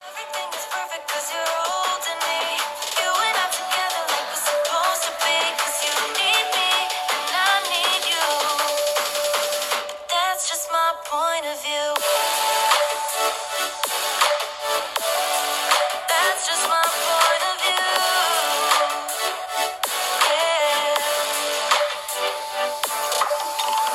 AQUOS wishのスピーカー(音質)をチェック
▼AQUOS wishのモノラルスピーカーの音はこちら！